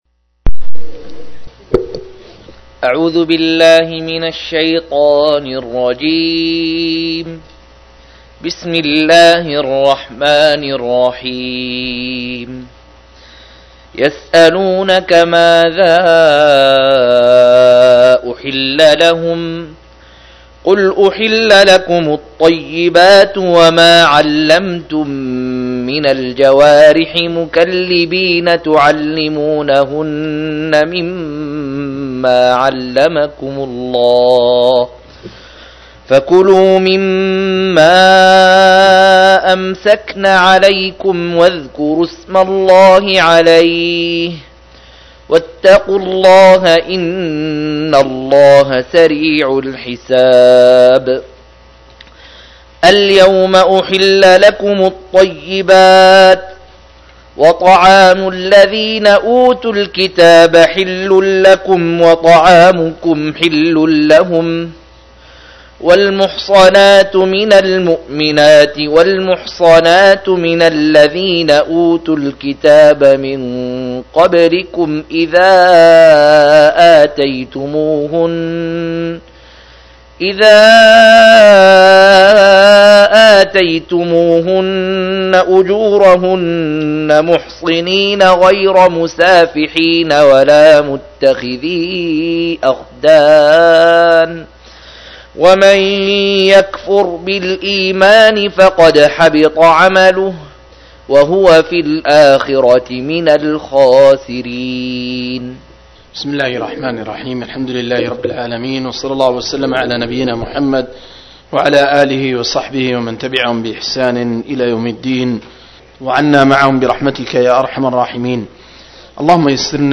109- عمدة التفسير عن الحافظ ابن كثير رحمه الله للعلامة أحمد شاكر رحمه الله – قراءة وتعليق –